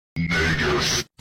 Clash Royale Free Evolution Baby Dragon Sound Effect Download: Instant Soundboard Button